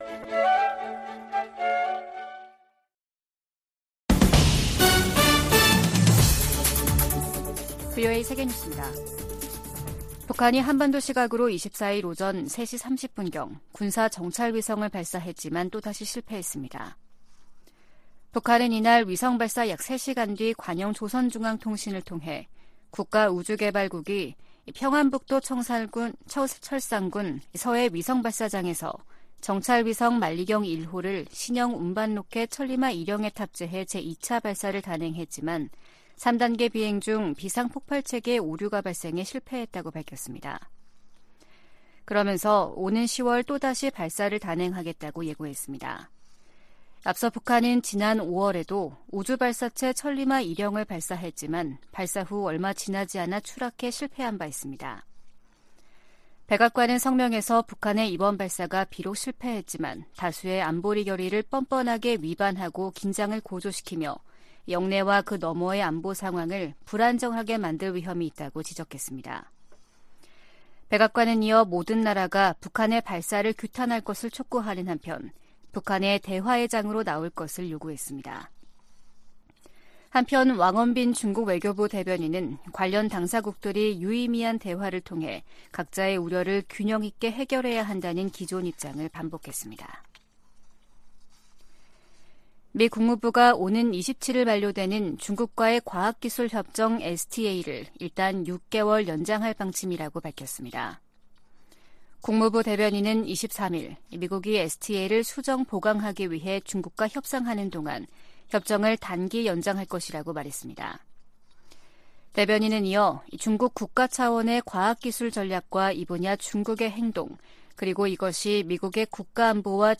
VOA 한국어 아침 뉴스 프로그램 '워싱턴 뉴스 광장' 2023년 8월 25일 방송입니다. 북한이 2차 군사 정찰위성 발사를 시도했지만 또 실패했습니다. 백악관은 북한 위성 발사가 안보리 결의에 위배된다고 규탄하며 필요한 모든 조치를 취할 것이라고 밝혔습니다. 미 국방부가 생물무기를 계속 개발하는 국가 중 하나로 북한을 지목했습니다.